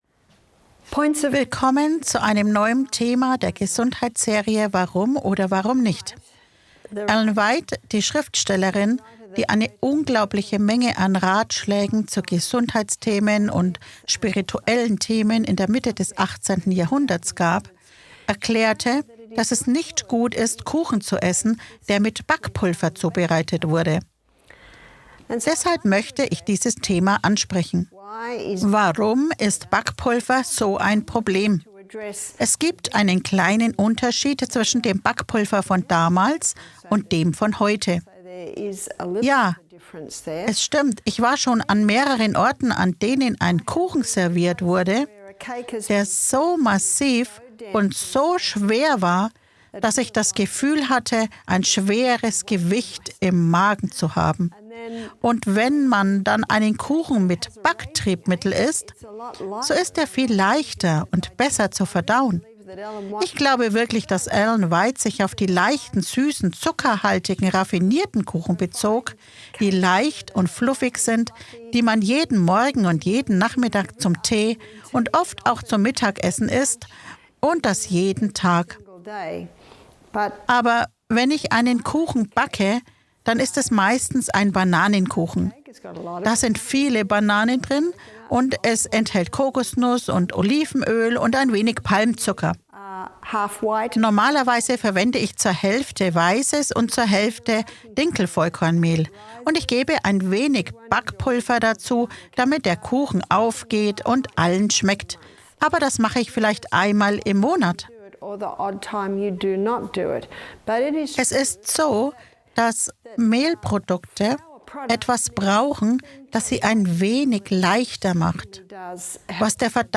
In diesem inspirierenden Vortrag wird untersucht, wie historische Ansichten zur Ernährung, insbesondere Ellen Whites Bedenken gegenüber Backpulver, die moderne Backkunst beeinflussen. Praktische Tipps und alternative Rezepte zeigen Wege auf, um köstliche, leicht verdauliche Kuchen und Brote zu kreieren.